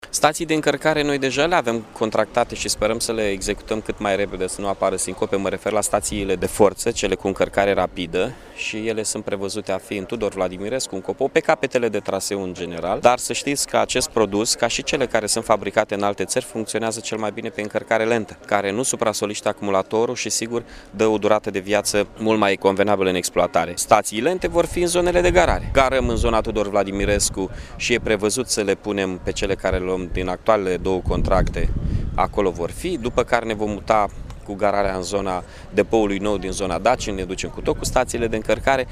Primarul municipiului Iași, Mihai Chirica, a spus că în acest moment se poziționează stațiile de încărcare rapidă pentru autobuzele electrice, dar vor fi montate și stații de încărcare lentă: